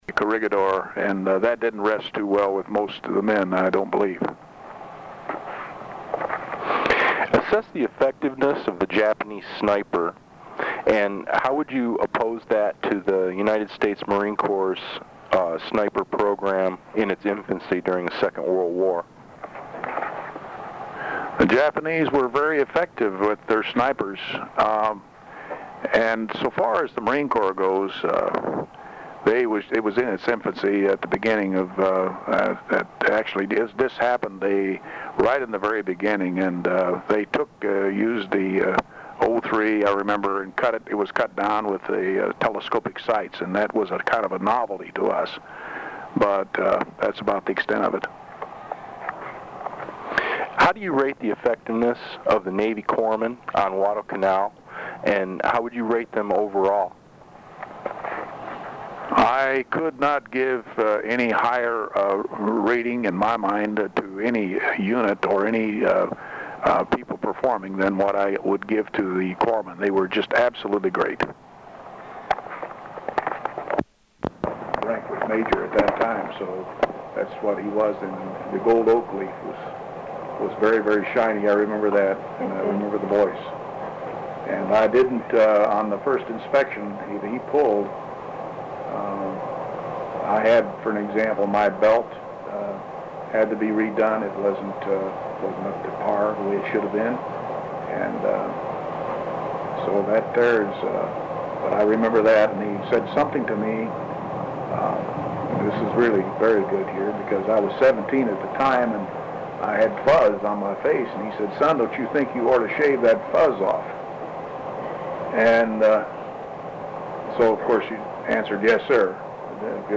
Oral History
Material Type Interviews